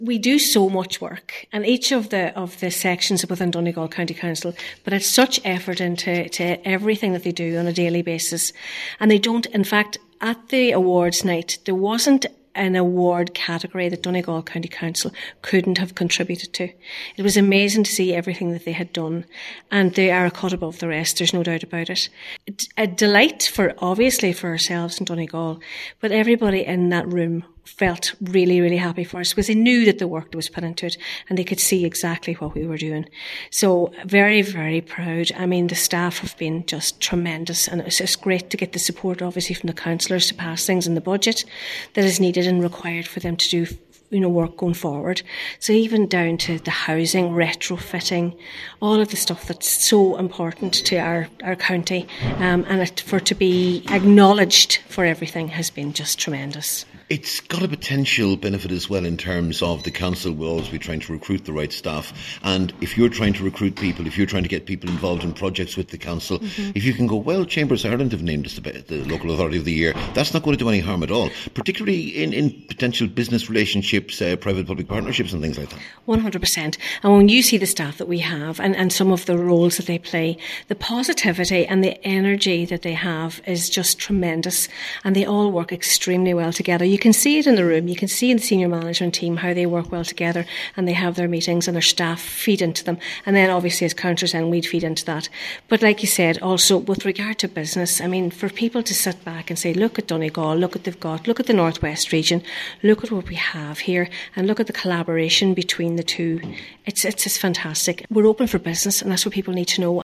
The Cathaoirleach of Donegal County Council says the council’s success at last week’s Chambers Ireland awards will pave the way for more growth and success.